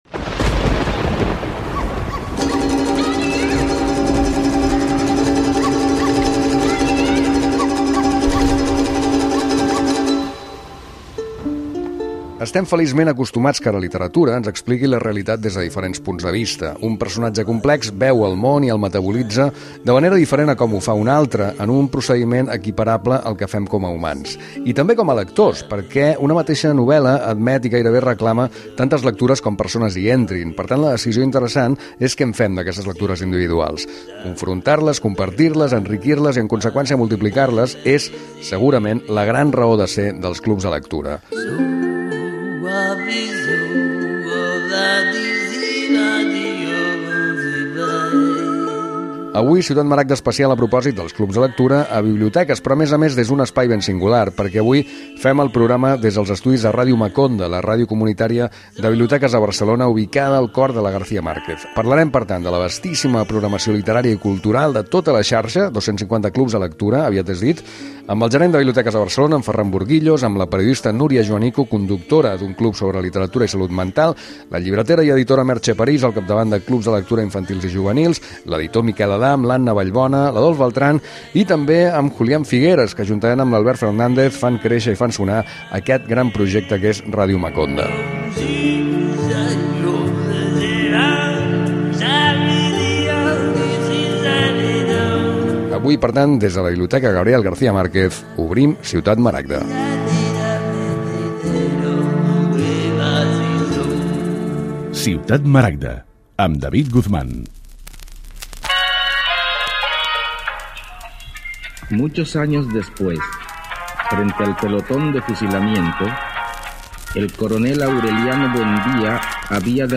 Espai fet des de l'estudi de Ràdio Maconda a la Biblioteca García Márquez de Barcelona.